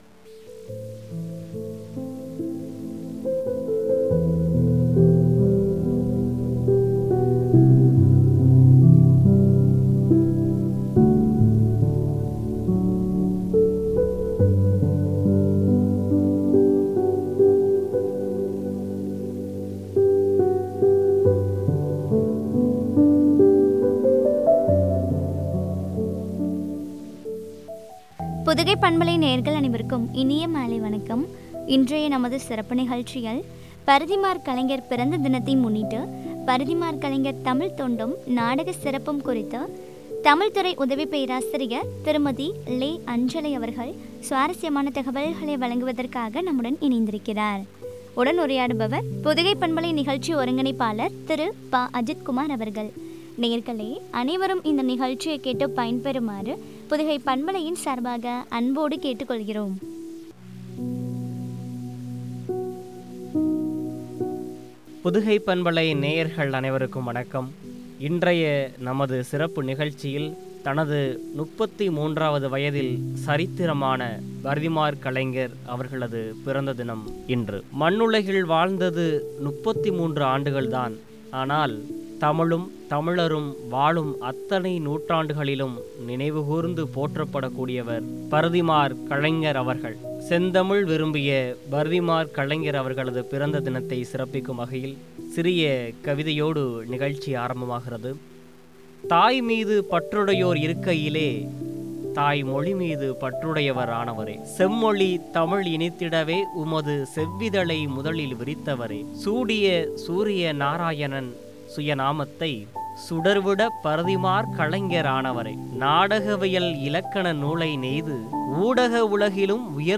நாடகச் சிறப்புகளும் குறித்து வழங்கிய உரையாடல்.